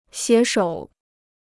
携手 (xié shǒu) Dictionnaire chinois gratuit